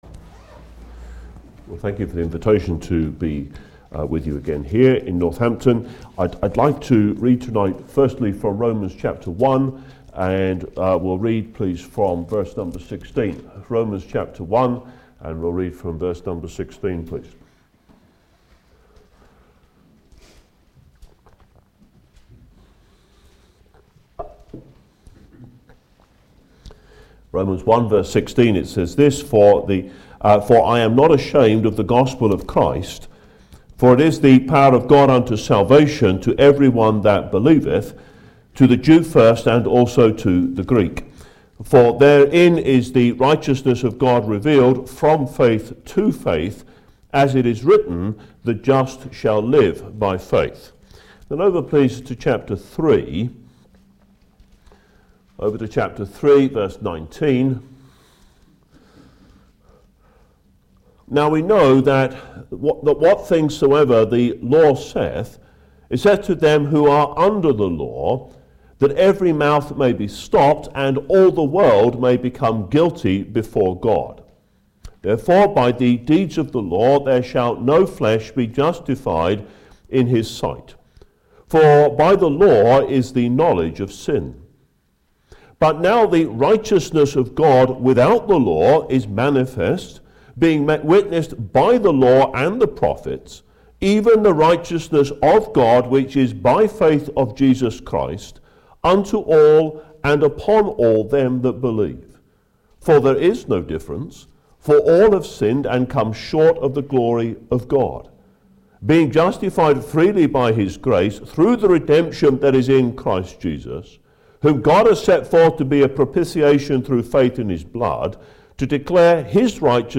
In this Saturday night bible teaching meeting
Passage: Romans 1:16-17, 3:19-31 Service Type: Ministry